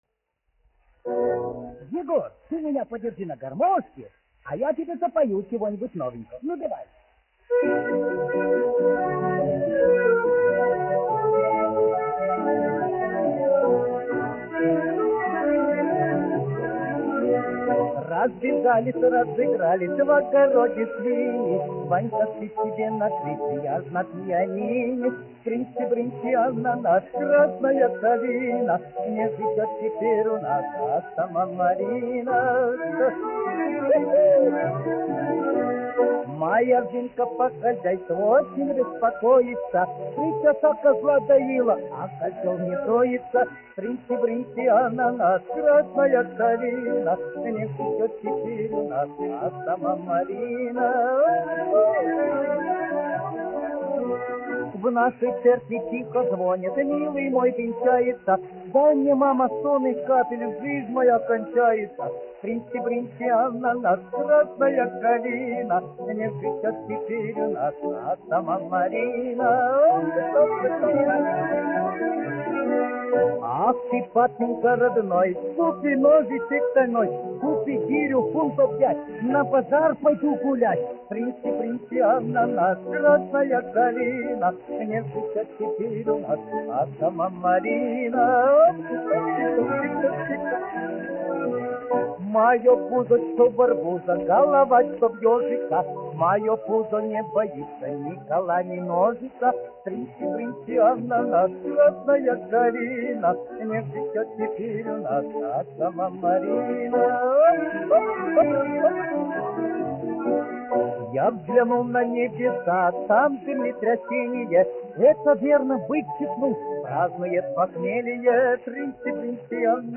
1 skpl. : analogs, 78 apgr/min, mono ; 25 cm
Častuškas
Krievu tautasdziesmas
Latvijas vēsturiskie šellaka skaņuplašu ieraksti (Kolekcija)